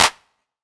Claps
Clp (Thin-Mint).wav